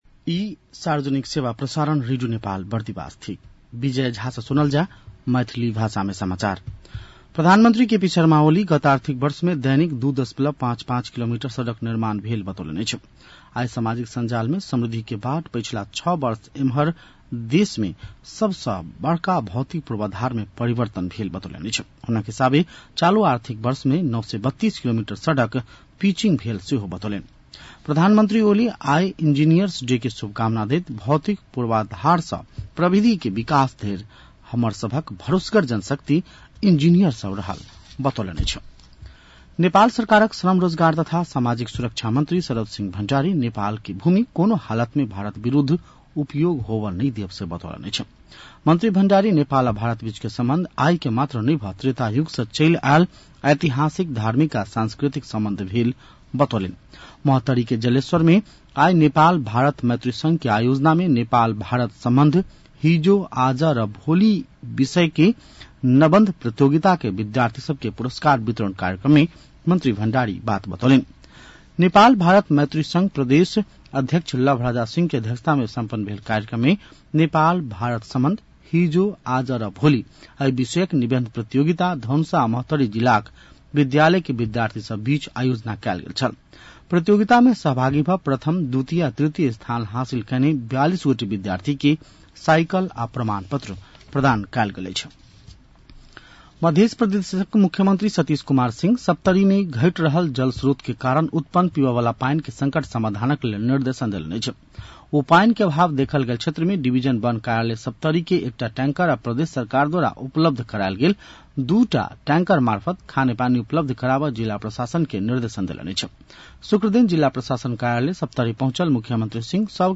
An online outlet of Nepal's national radio broadcaster
मैथिली भाषामा समाचार : ३ साउन , २०८२